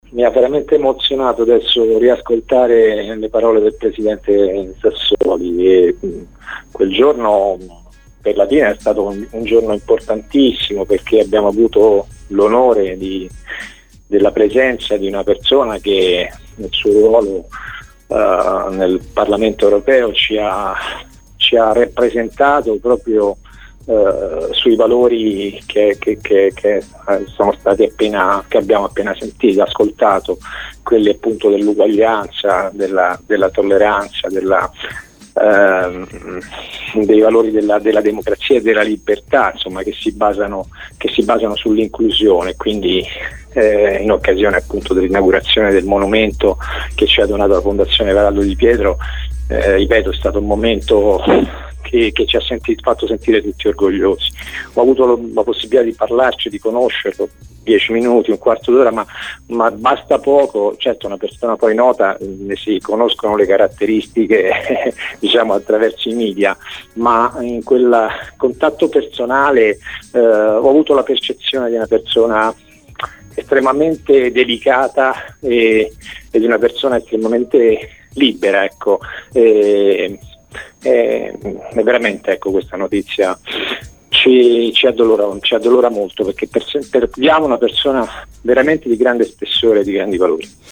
Con noi oggi su Radio Immagine il ricordo del sindaco di Latina Damiano Coletta: “Fieri di averlo avuto a Latina con noi. Ho avuto l’onore di conoscerlo, in quel contatto personale ho avuto la percezione di una persona estremamente delicata e  veramente libera, questa notizia ci addolora, perdiamo una persona di grande spessore”, ha detto il primo cittadino di Latina